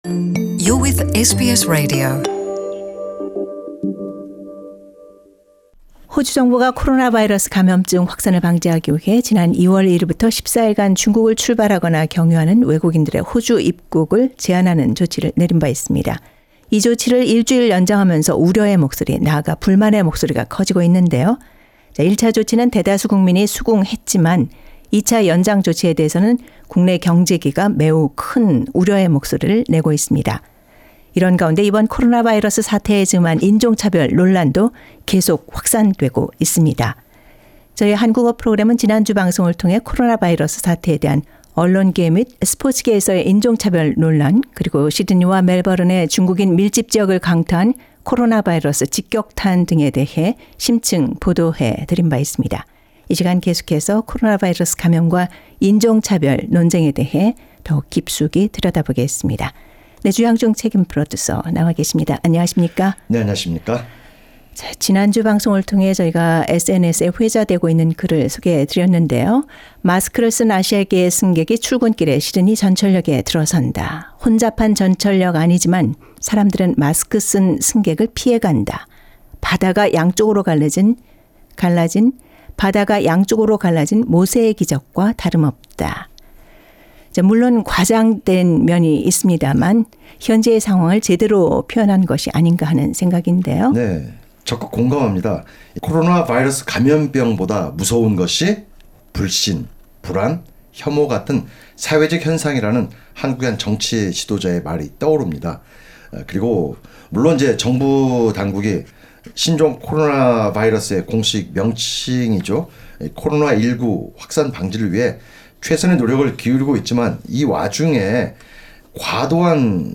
SBS 한국어 프로그램